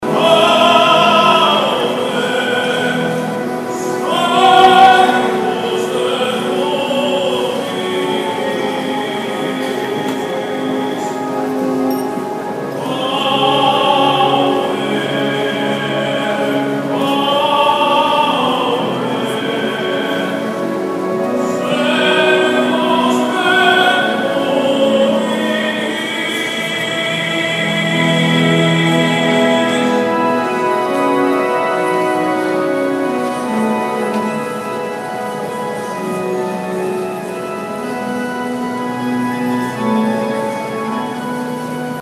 El tenor italiano comenzó, sorpresivamente, a cantar himnos religiosos el Domingo de Resurrección en la Iglesia Saint Patrick (San Patricio) de Miami Beach.
Andrea Bocelli canta en la Iglesia Saint Patrick